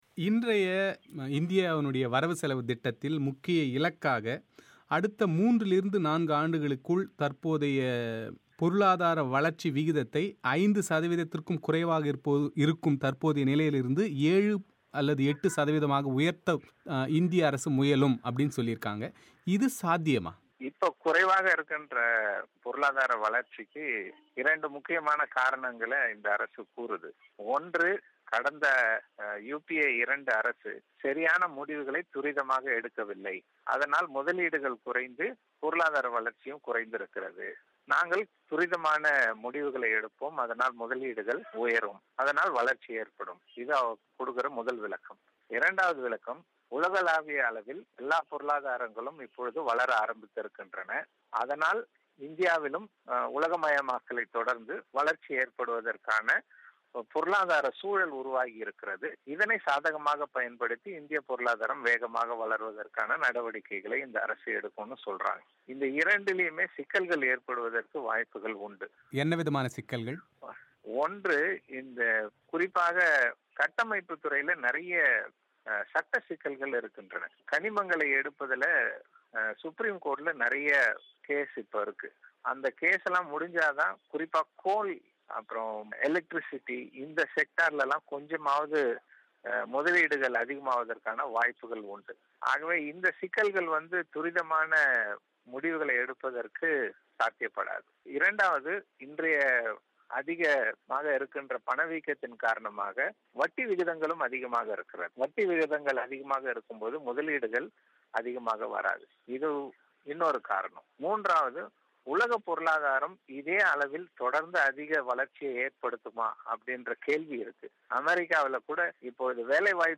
பிபிசி தமிழோசைக்கு அளித்த விரிவான ஆய்வுக்கண்ணோட்ட செவ்வியை நேயர்கள் இங்கே ஒலி வடிவில் கேட்கலாம்.